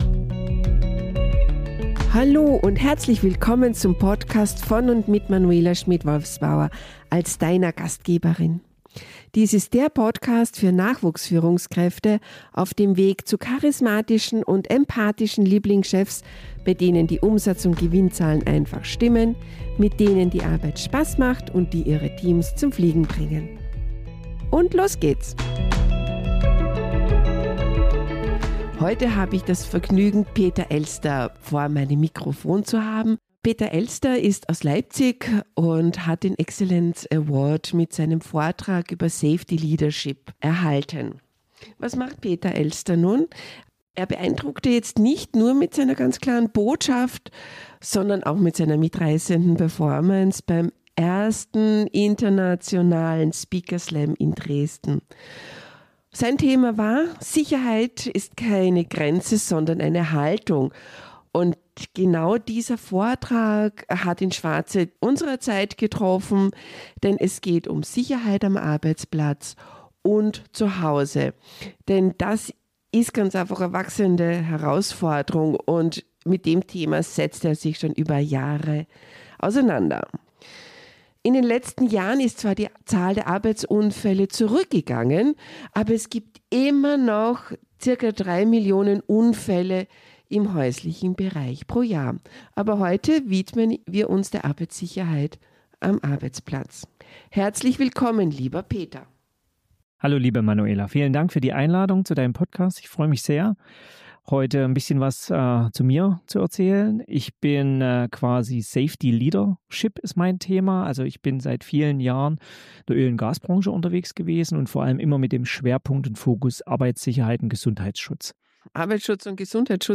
Ein Gespräch voller wertvoller Impulse!